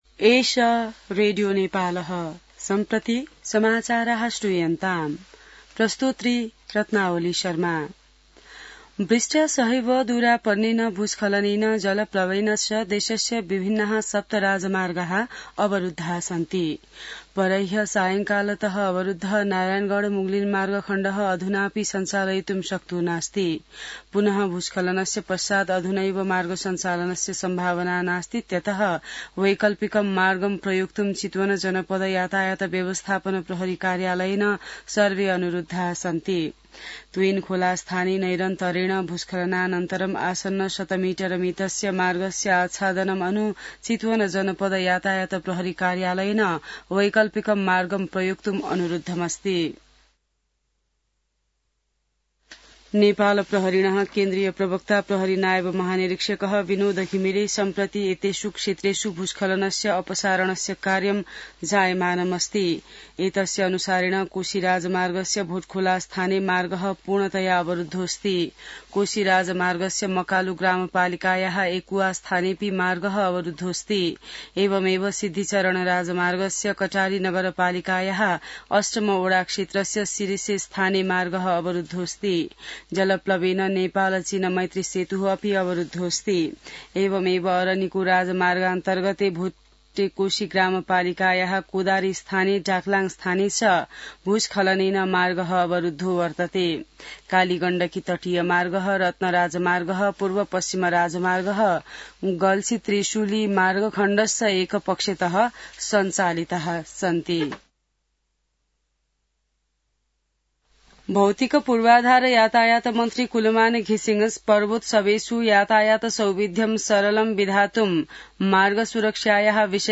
संस्कृत समाचार : ५ असोज , २०८२